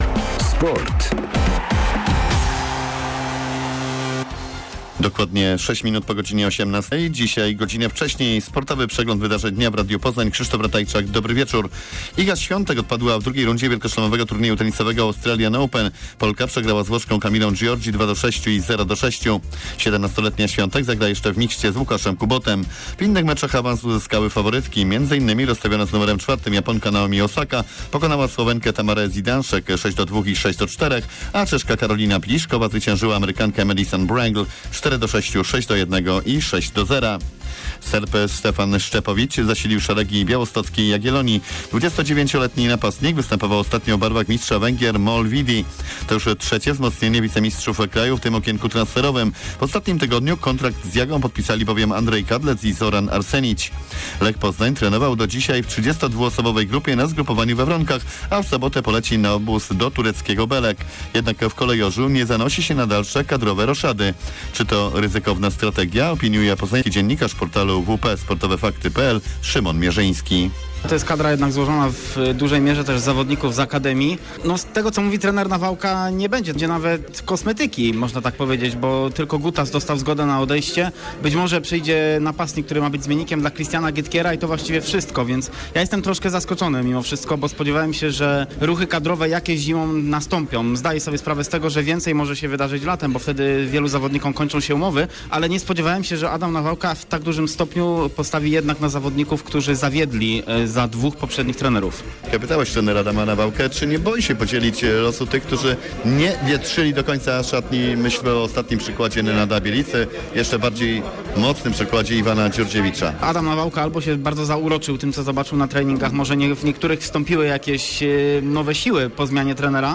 17.01 serwis sportowy godz. 18:05